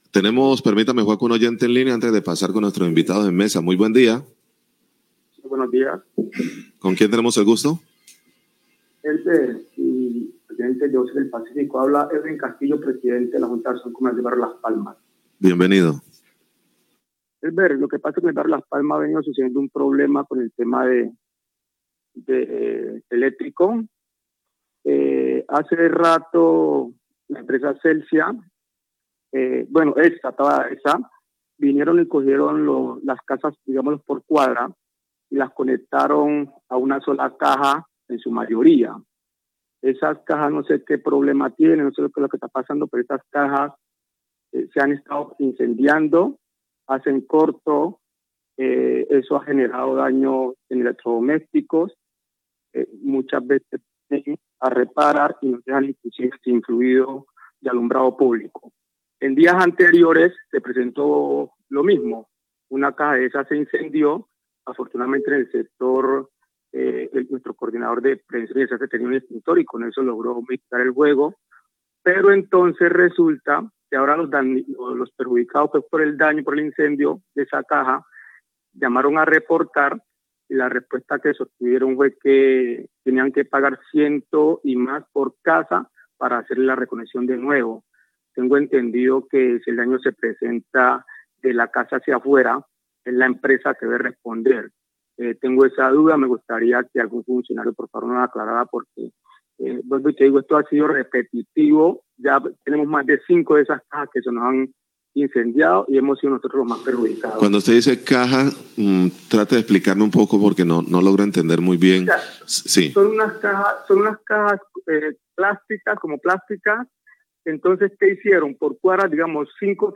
Oyente lanza interrogante sobre arreglo de daños en cajas de la empresa de energía Celsia
Radio